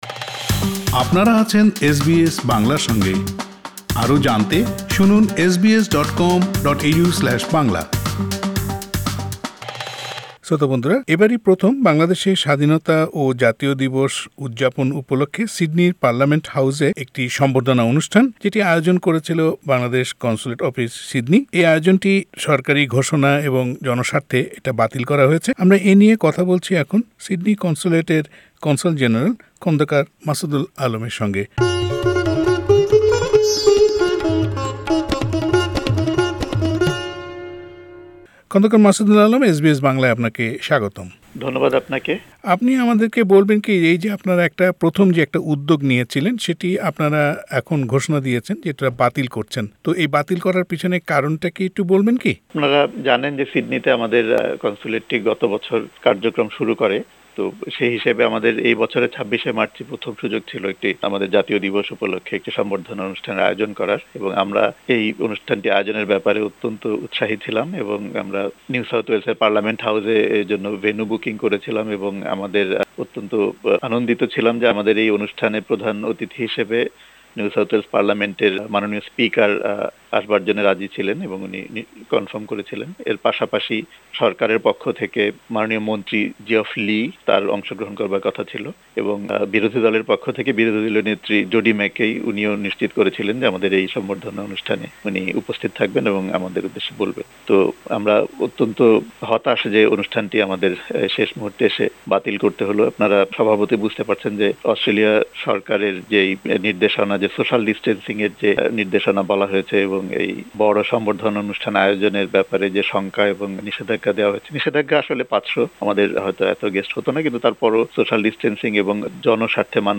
এ নিয়ে কথা বলেছি কনসাল জেনারেল খন্দকার মাসুদুল আলমের সঙ্গে। সাক্ষাৎকারটি শুনতে উপরের লিংকটিতে ক্লিক করুন।